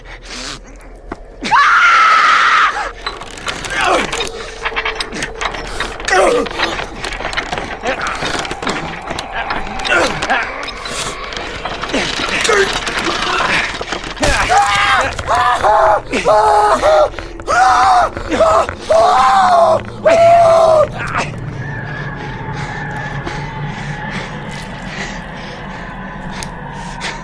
Звуки ужаса или для создания эффекта чего-то ужасного для монтажа видео
11. Крики ужаса и стоны